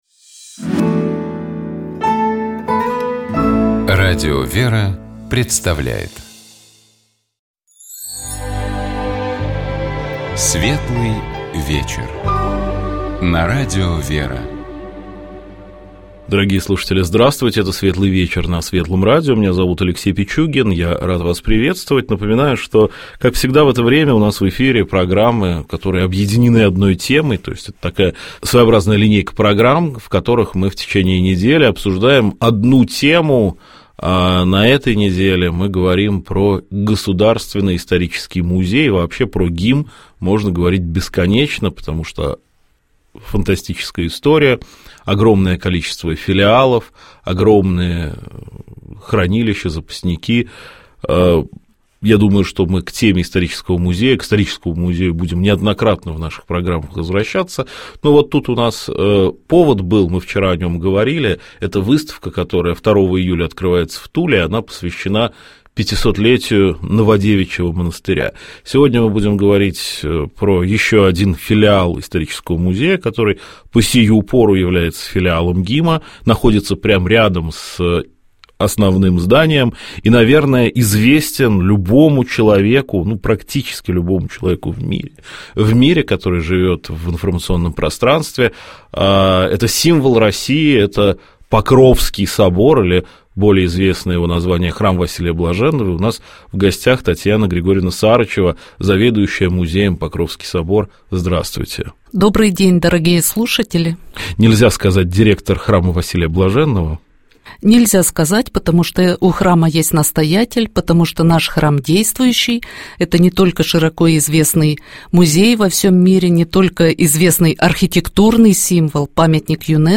Мы говорили о том, почему и каким образом важно заботиться о здоровье голоса тем, у кого работа связана с его использованием, а также о том, как голос может отражать внутреннее состояние человека.